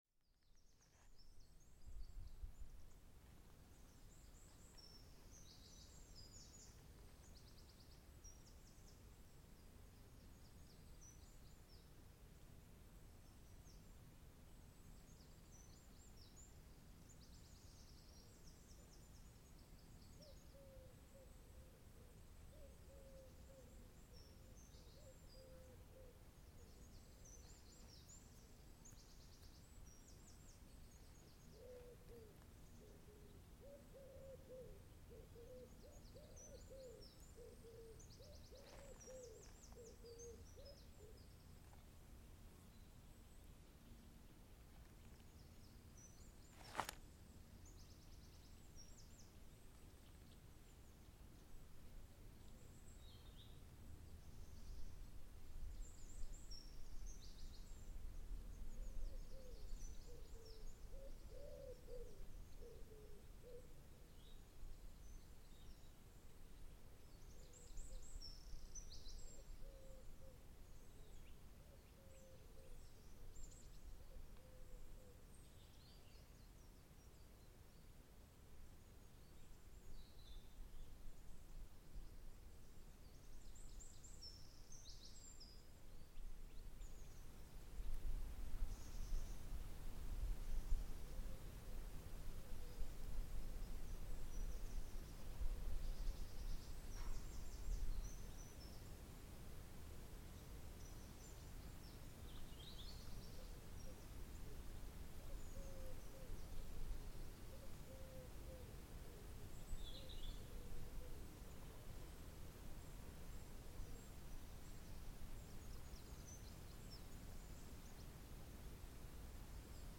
Birdsong, breeze and trees
A peaceful moment next to a small lake as we listen to birdsong, the breeze gently ruffling the leaves of the trees above us, and the occasional insect buzzing past the microphone.
Recorded in Kintbury, England by Cities and Memory.